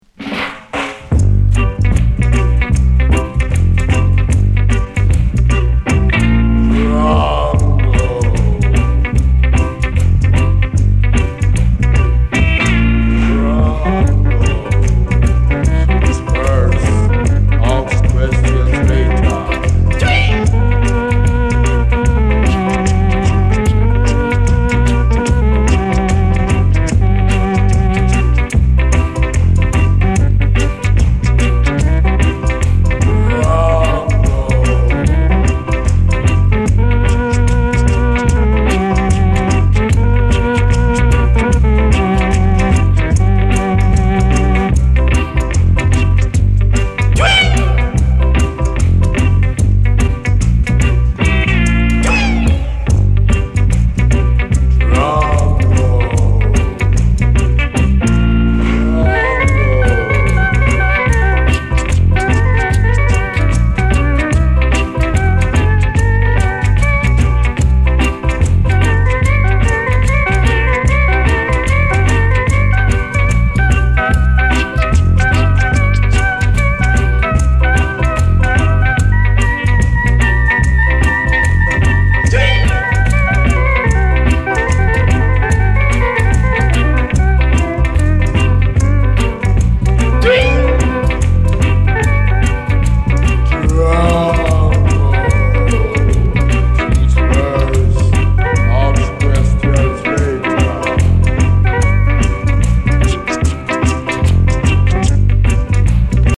銃声やハモンド効かせたスキンズ・チューンなどルーディー・チューン満載！